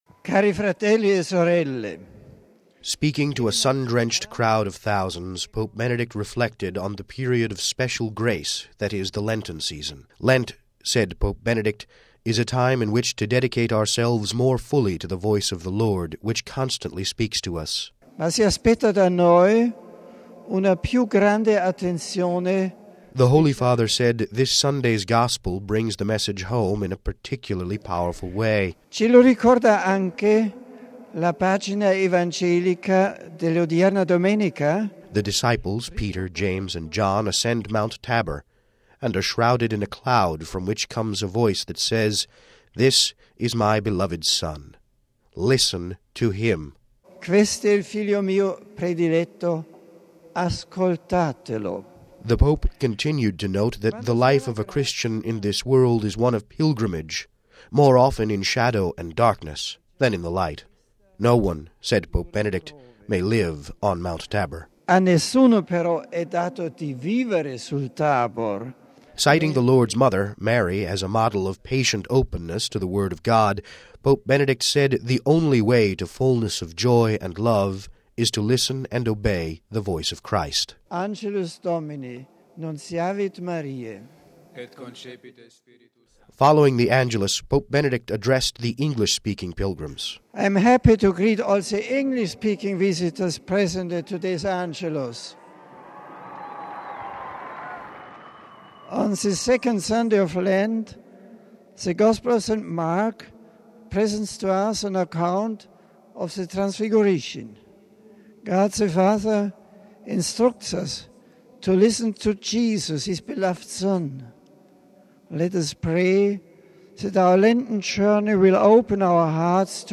(12 Mar 06 - RV) Thousands of pilgrims filled St Peter’s Square beneath Pope Benedict XVI office window today to pray the Sunday Angelus with him.